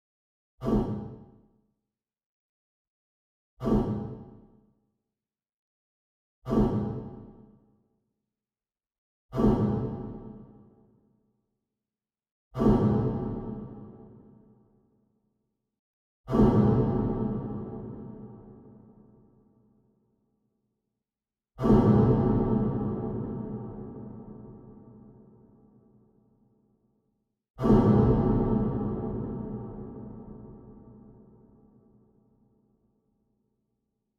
Metal Tube - Clear
hit hitting horror horror-impacts impact industrial iron knock sound effect free sound royalty free Memes